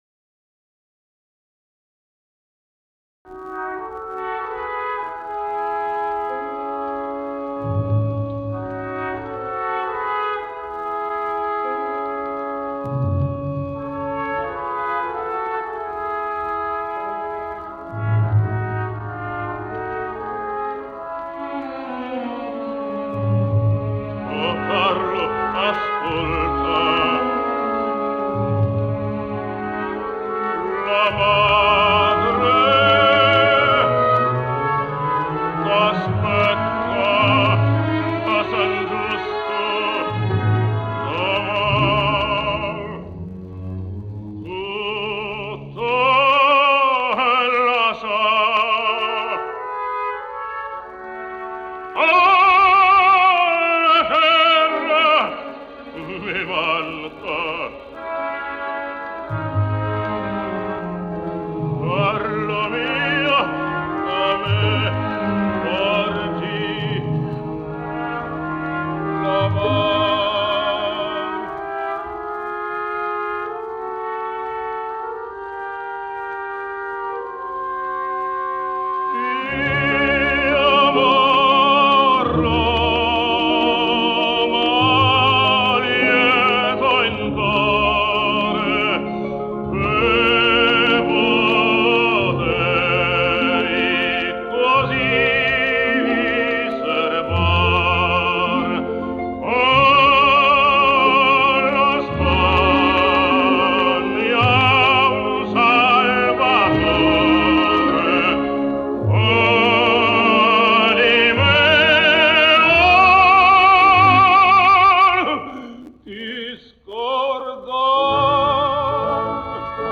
98 лет со дня рождения итальянского певца (баритон) Джино Беки (Gino Bechi)
Д.Верди-Дон Карлос-ария маркиза Ди Позы-исполняет Джино Беки